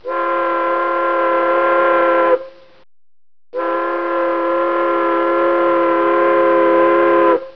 Tug Boat Horns
Small Tug boat horn - Large Tug boat horn